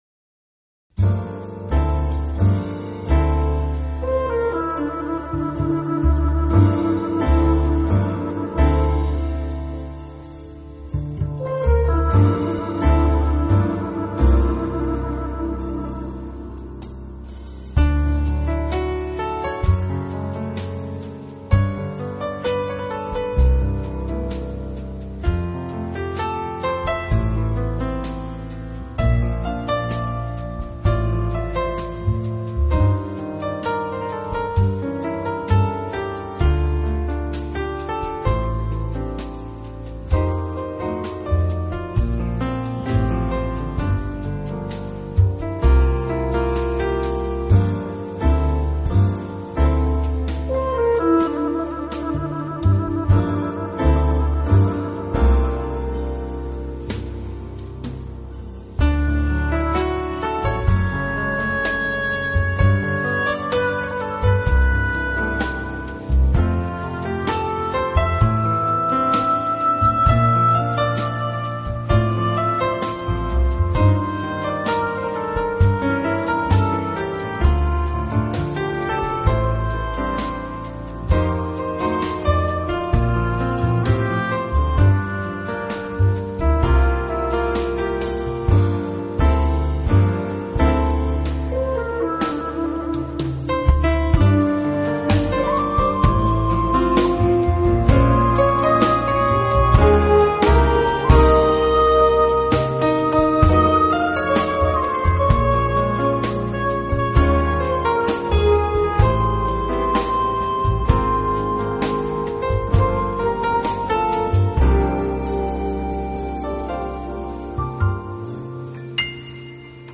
音乐类型：Jazz, Piano
则象一支夜曲，在曲子中，长笛的出现是非常
让人惊喜的，而完全脱离爵士的旋律则象春天夜间的微风。